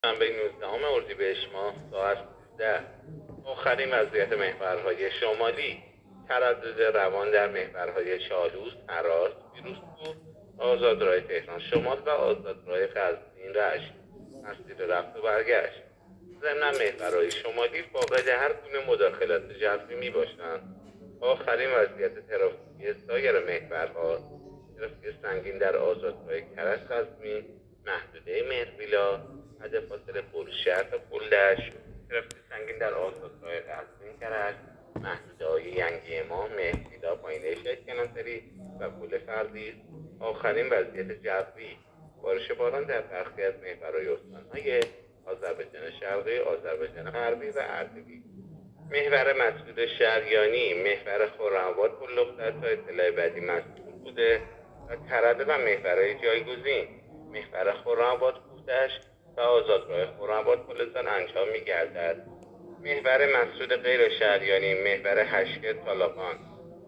گزارش رادیو اینترنتی از آخرین وضعیت ترافیکی جاده‌ها تا ساعت ۱۳ نوزدهم اردیبهشت؛